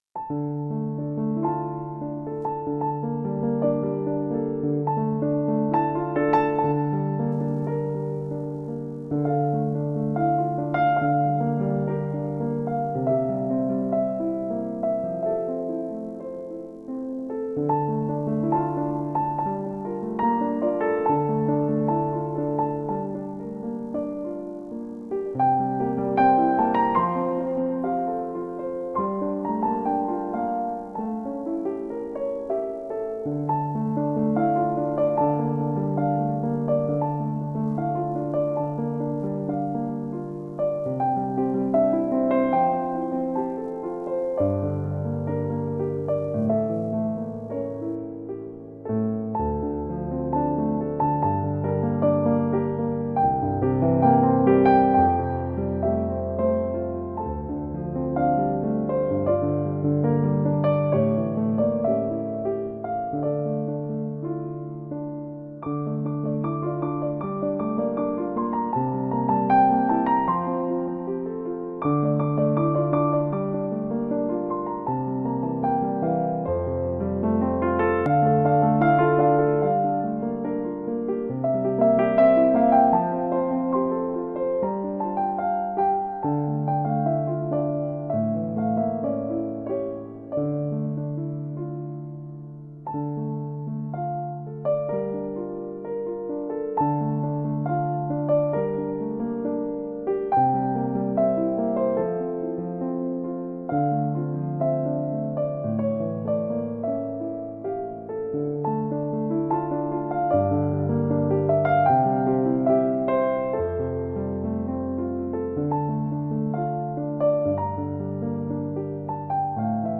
New age romantic piano music.